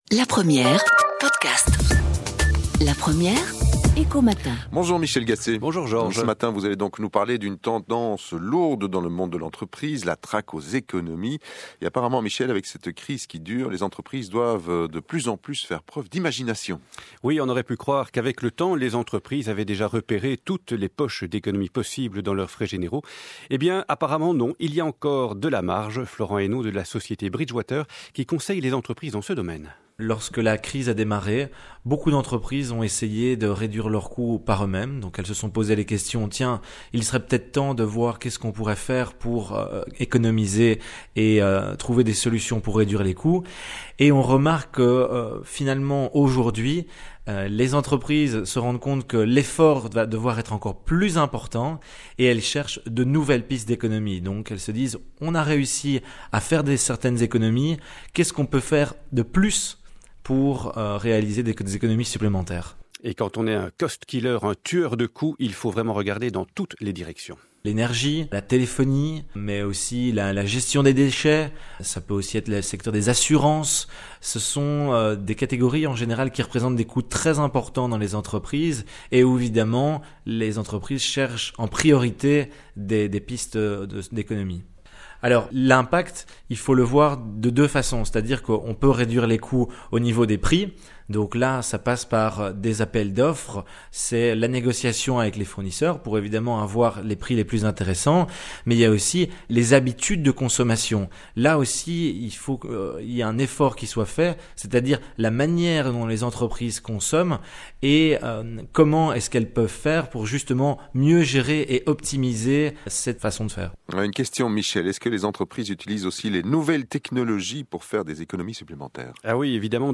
Bridgewater | Interview Bridgewater op La Première (RTBF)